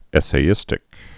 (ĕsā-ĭstĭk)